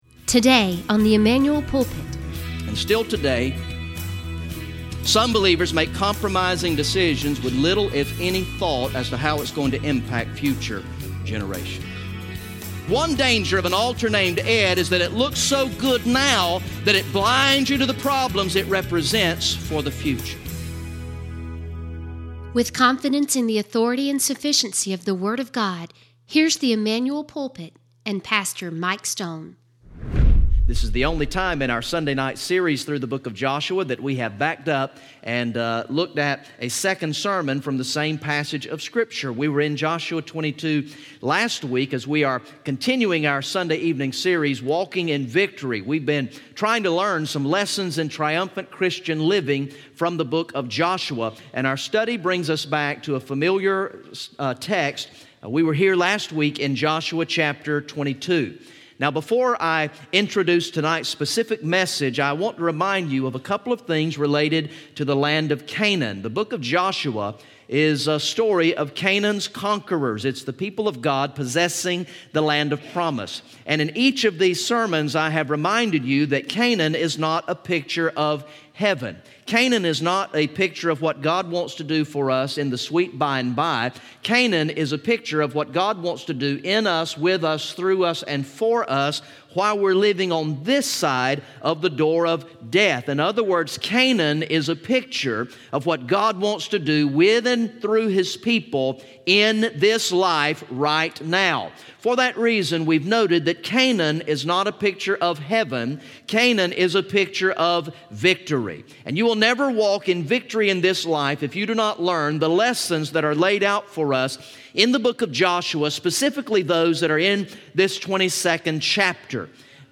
From the sermon series through the book of Joshua entitled "Walking in Victory" Recorded in the evening worship service on Sunday, March 18, 2018